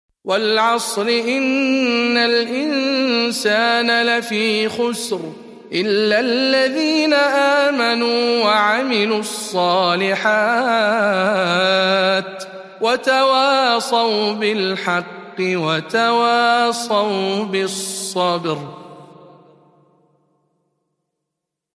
102. سورة العصر برواية شعبة عن عاصم - رمضان 1441 هـ